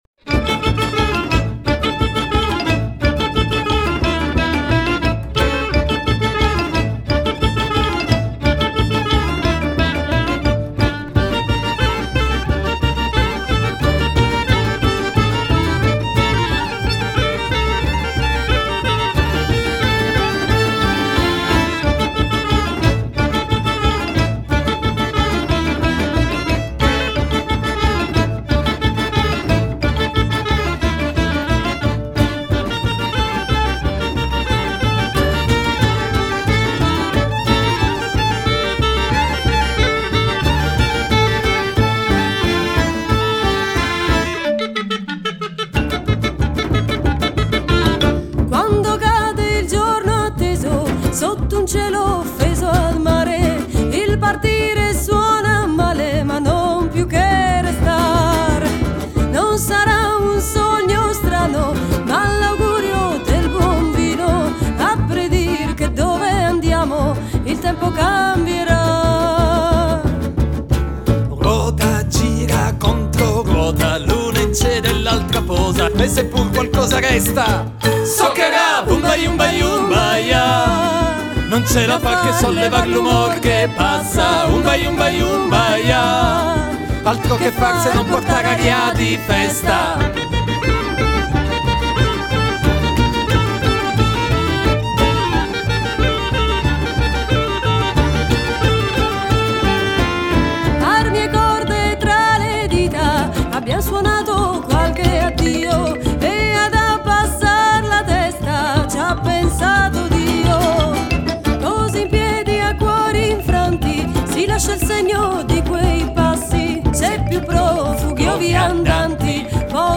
chitarra e voce
voce e sonagli
contrabbasso
violino
flauto traverso, clarinetto, chalumeaux, chitarra ac.
batteria, percussioni, marimba, fisarmonica, piano
tapan, darabukka, tamburello, tabla, percuss. e colori